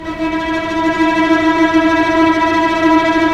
Index of /90_sSampleCDs/Roland - String Master Series/STR_Vcs Tremolo/STR_Vcs Trem p